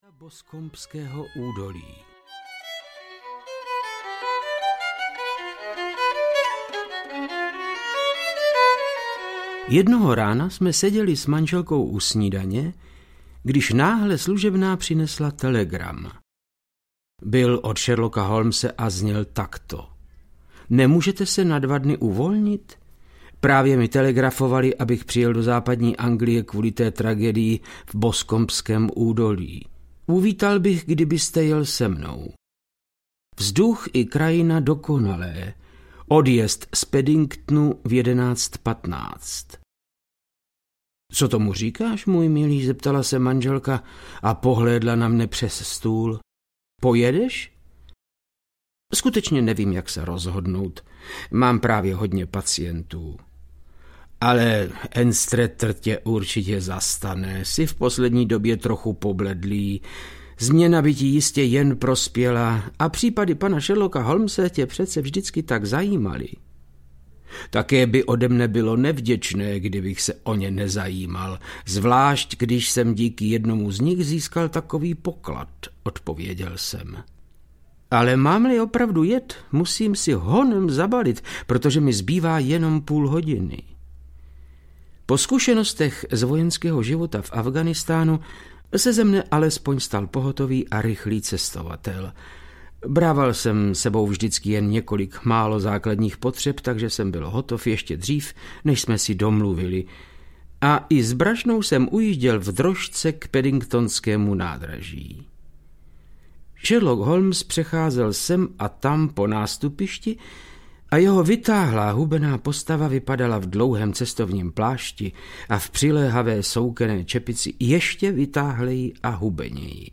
Záhada Boscombského údolí audiokniha
Ukázka z knihy
• InterpretVáclav Knop